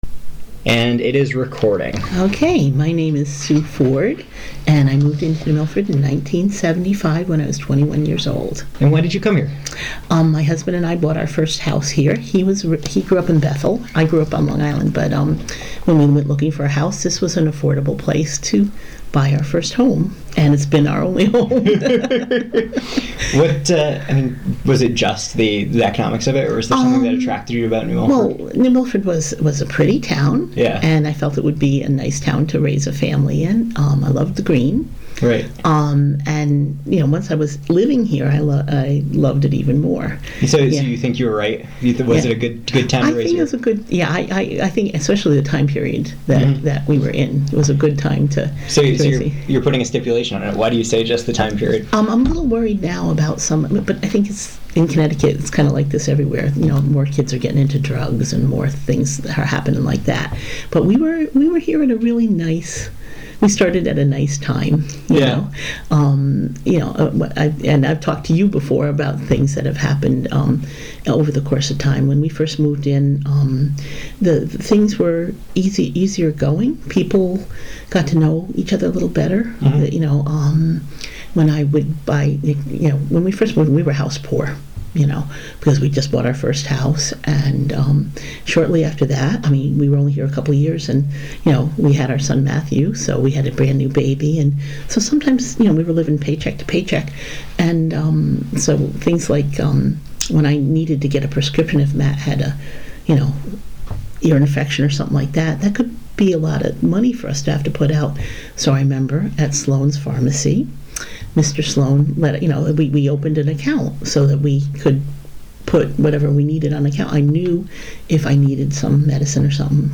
Oral History
Location New Milford Public Library